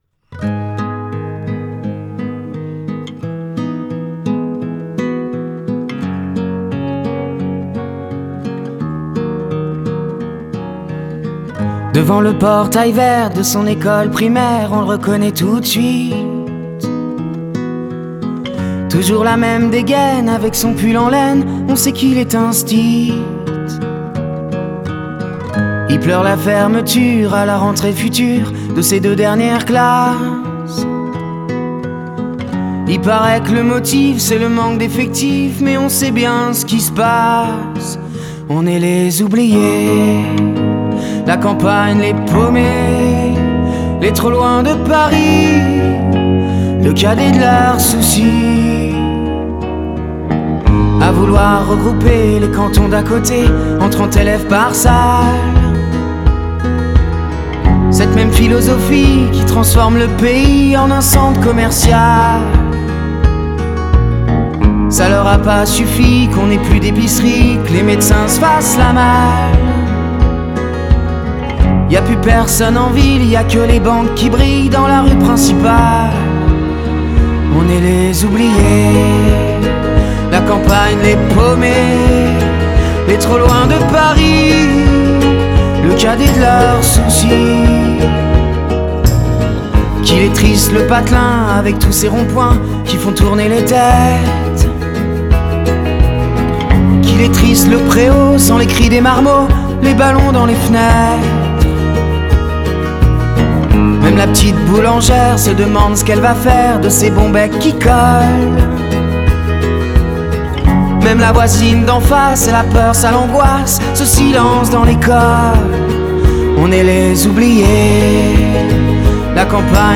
ترانه فرانسوی chanson française